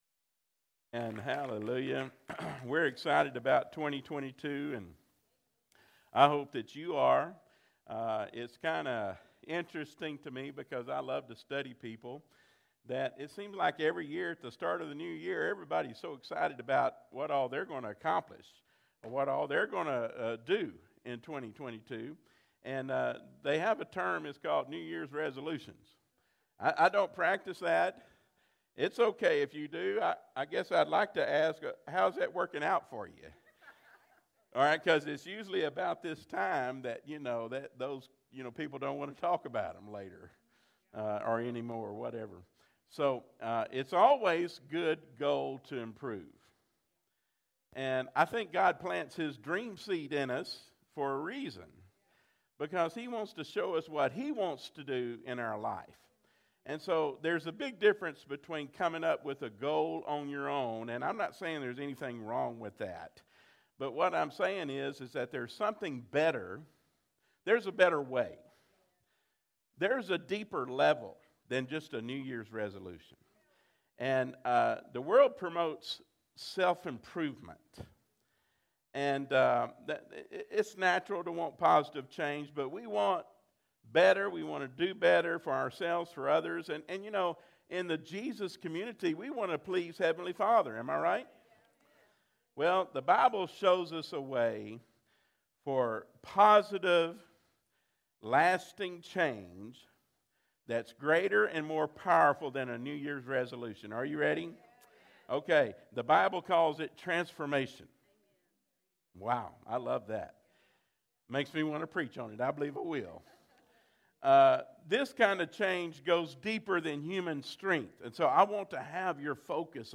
delivers a message on how the Bible shows us the way for positive, lasting change - It's called transformation.